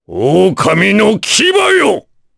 Kaulah-Vox_Skill5_jp.wav